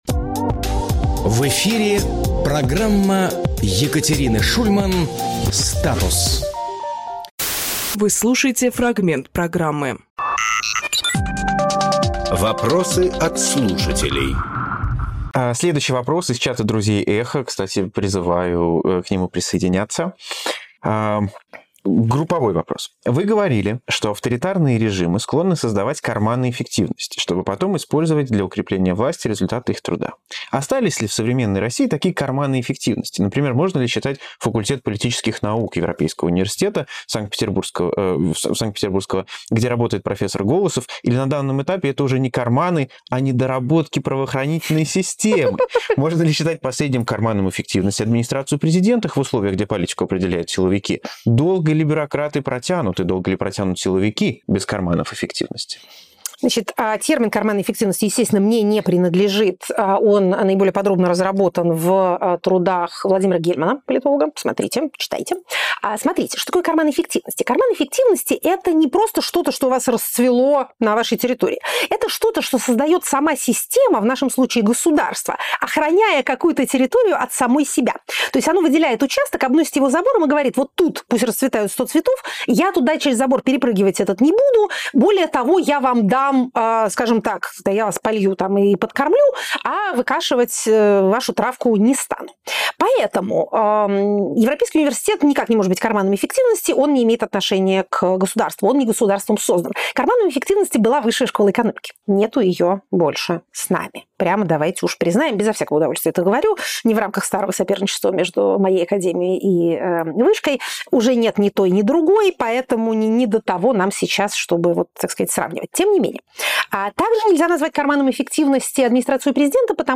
Екатерина Шульманполитолог
Фрагмент эфира от 09.04.24